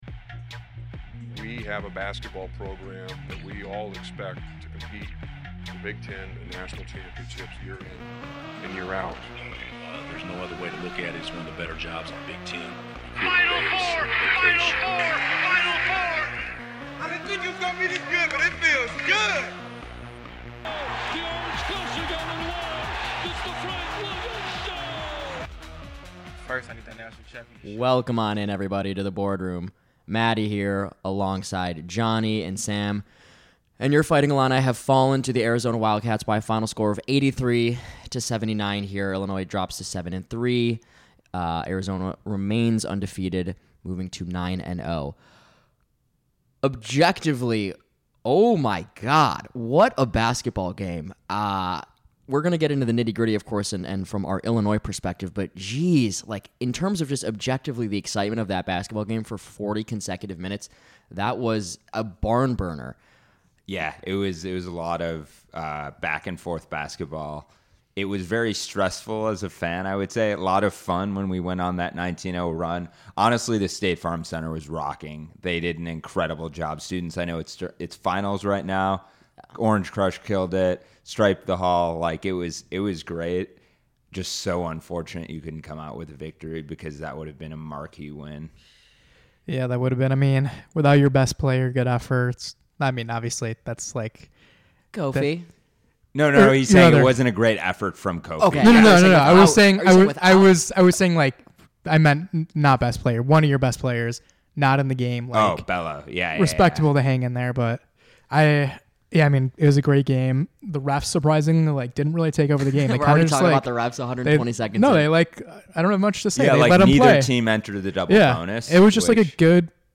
A thrilling game ends in crushing defeat for Illinois as they can't hold on against the Bearcats. The guys talk their way through this one and calmly explain how the Illini are just fine.